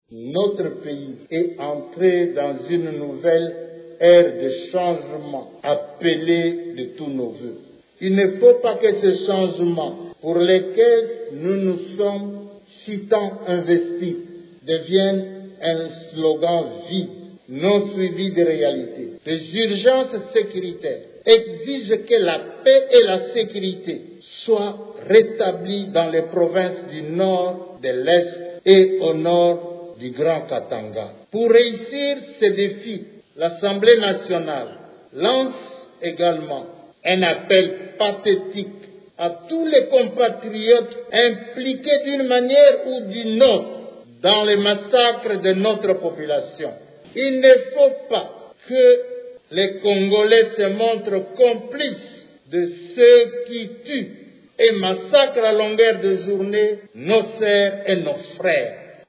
Dans son adresse au cours de la plénière de ce lundi 29 mars, il a appelé les Congolais à se désolidariser de groupes armés, qui sèment la désolation dans le nord-est de la RDC.
Le président de l’Assemblée nationale a lancé un appel pathétique à tout Congolais, impliqué d’une manière ou d’une autre dans les massacres de la population congolaise, à cesser la collaboration avec les groupes armés :